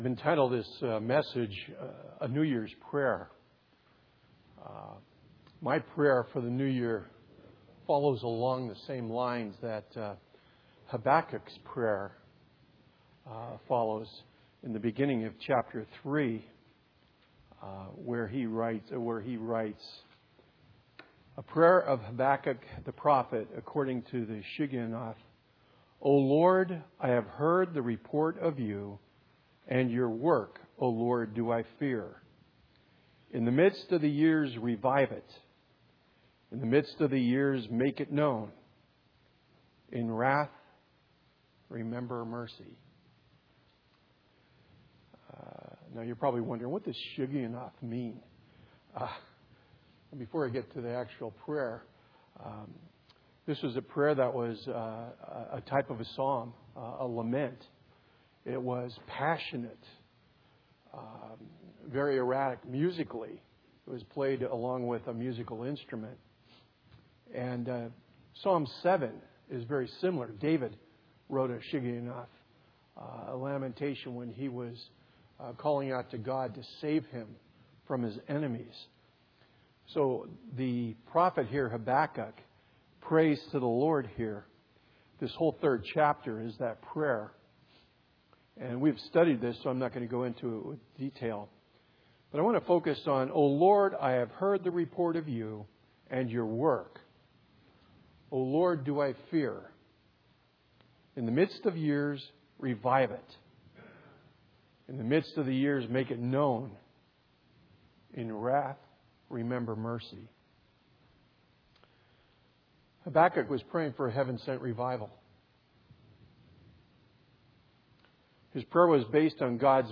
2017 Sermons